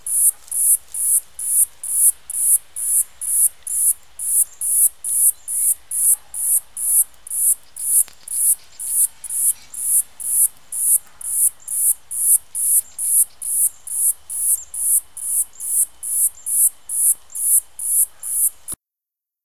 Cigale noire Cicadatra atra